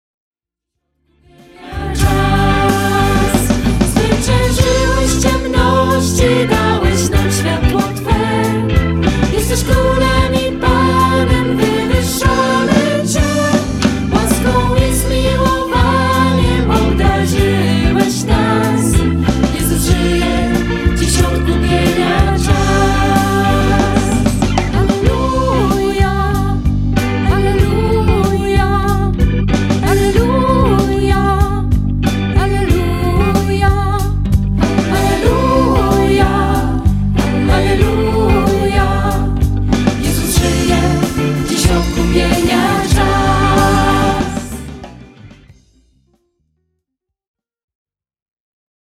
De très beaux chants d'assemblée